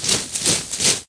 soldier ant.ogg